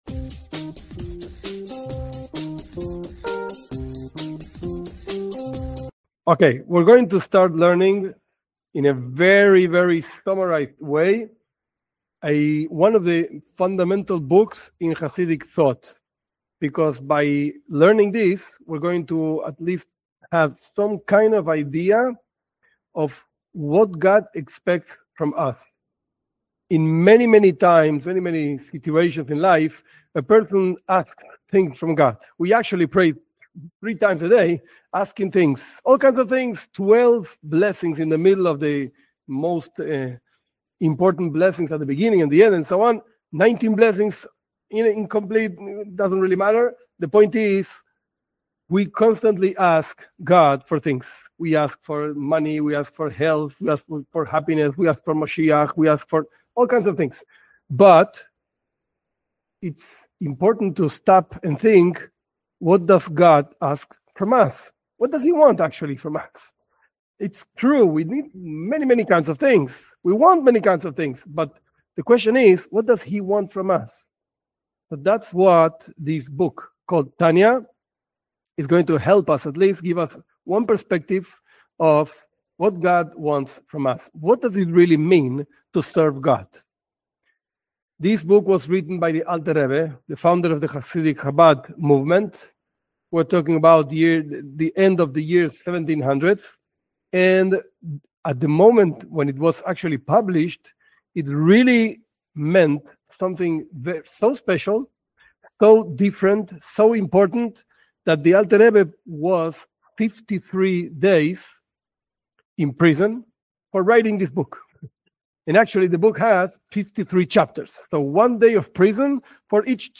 This class is the first of a series where the most basic book of Chabad Chassidism is summarized. The most basic themes and ideas of the Jewish perspective on serving God.